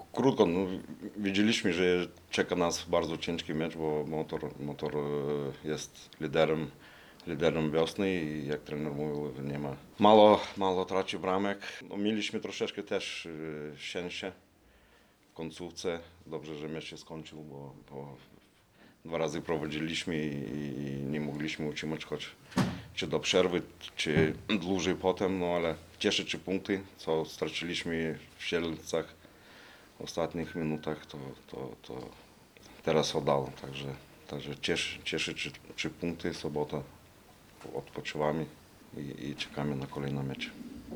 Suwałki – Stadion Miejski